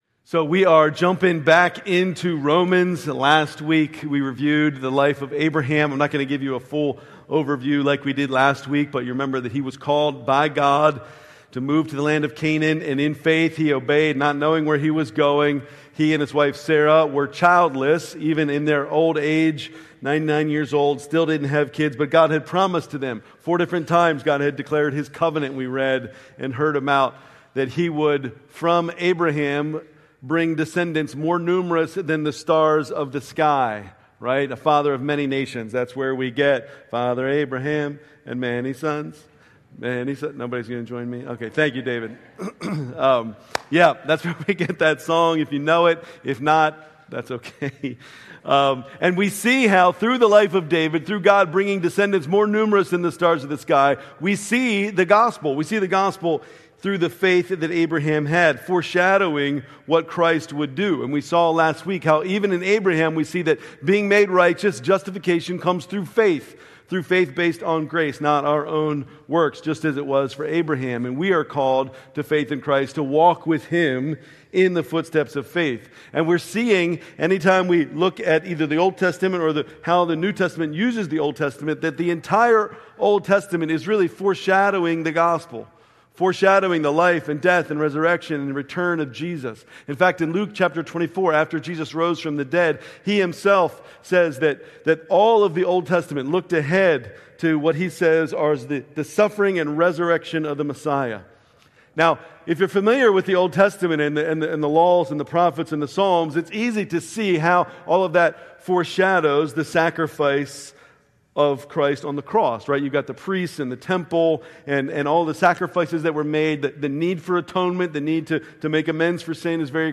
November 2, 2005 Worship Service Order of Service: